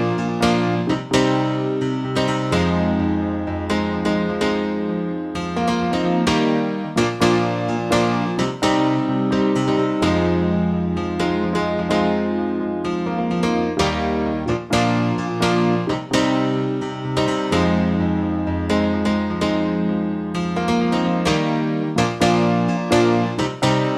No Bass Guitar, No Guitar, No Drumkit